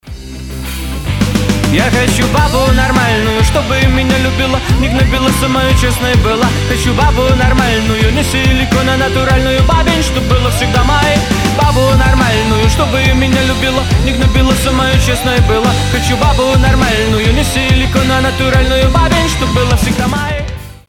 • Качество: 320, Stereo
веселые
ска-панк